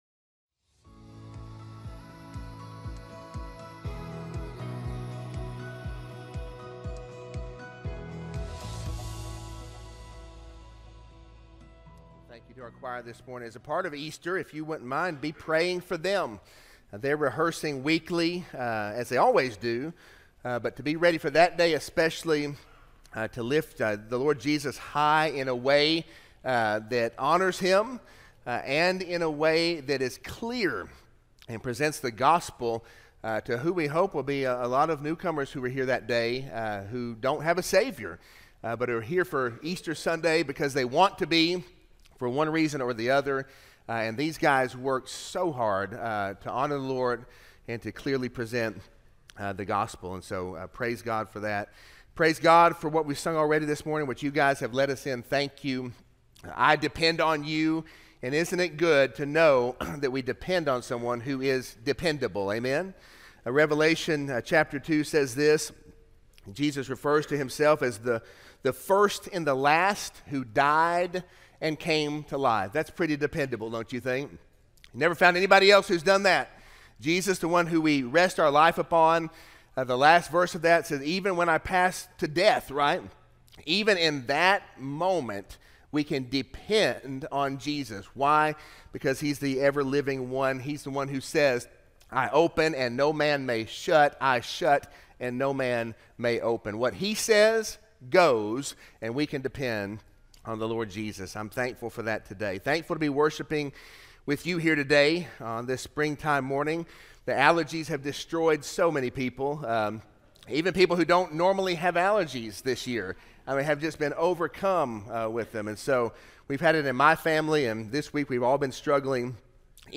Sermons – Poplar Springs Baptist Church
Sermon-4-6-25-audio-from-video.mp3